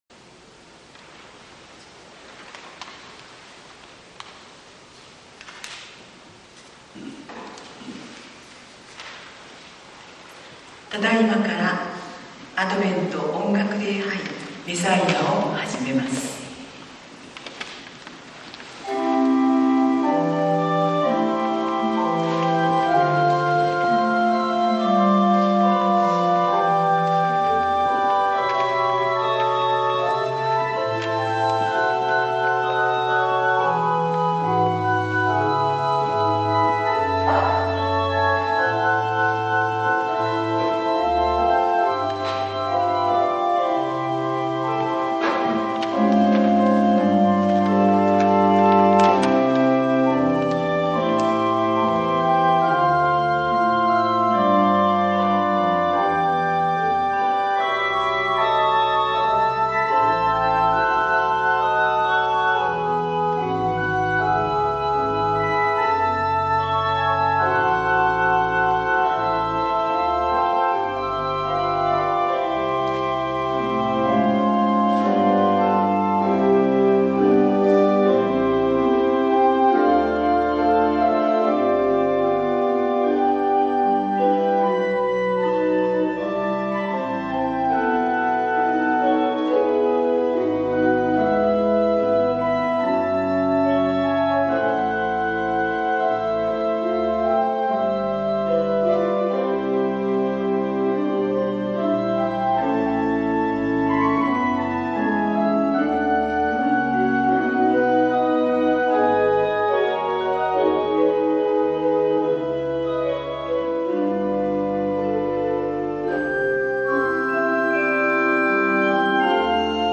アドヴェント音楽礼拝「メサイア」－被爆・敗戦８０年をおぼえて－